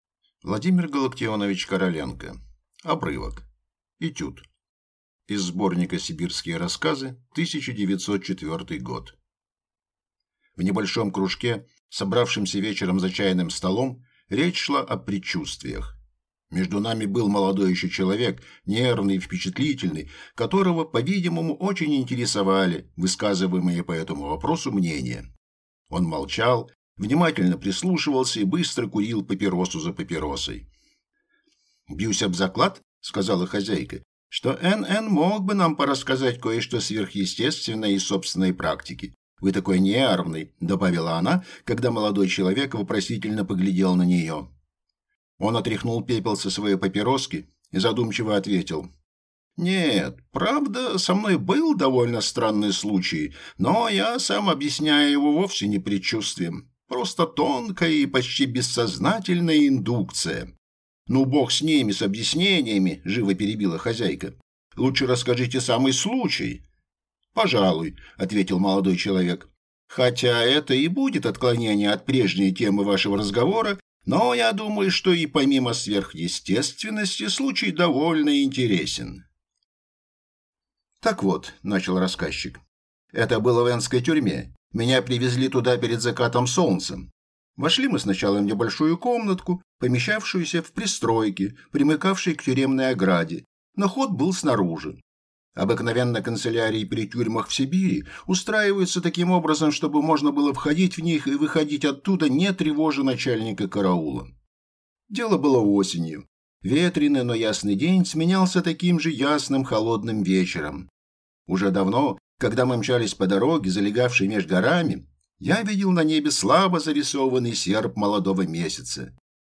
Aудиокнига Обрывок